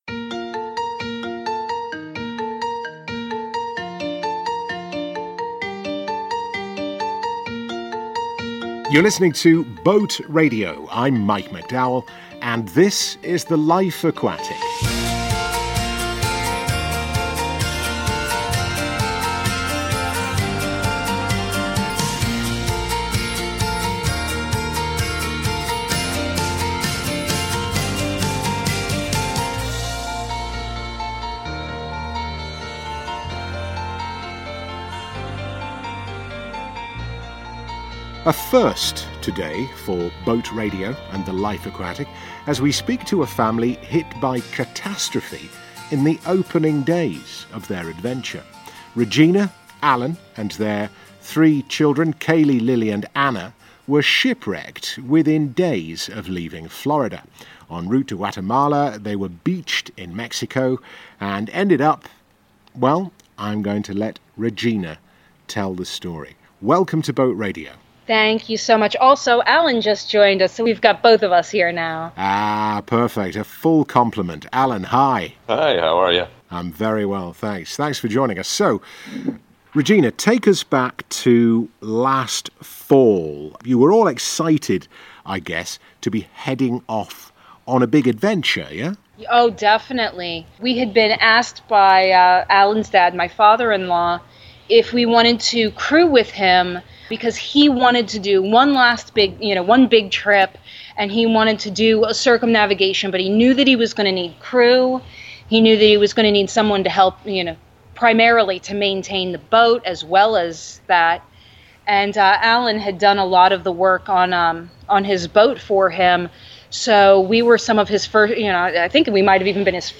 This interview is in two parts.